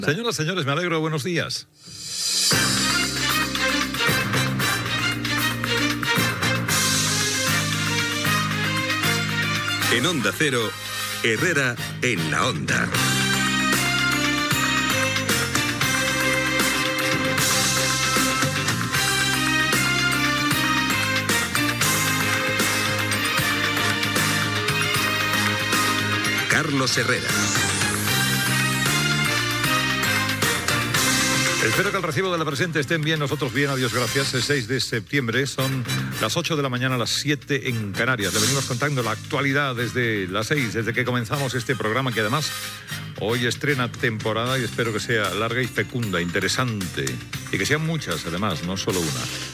Salutació des del primer programa matinal de Carlos Herrera a Onda Cero.
Info-entreteniment
FM